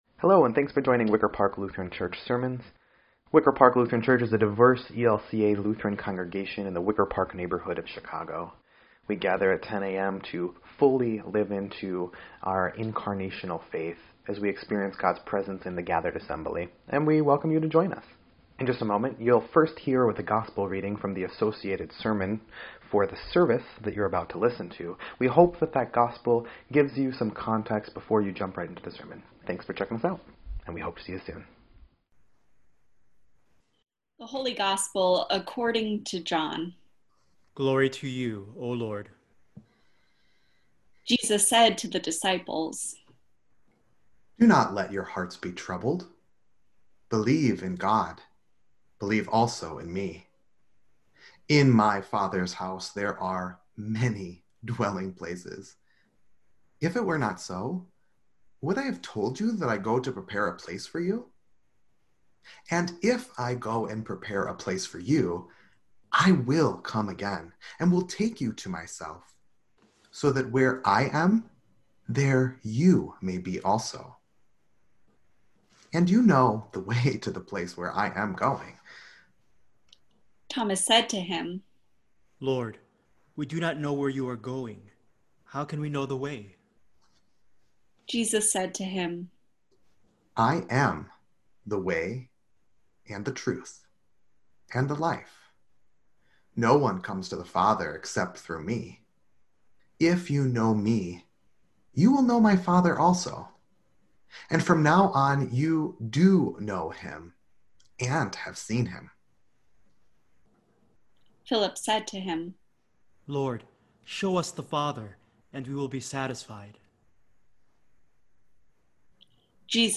5.10.20-Sermon.mp3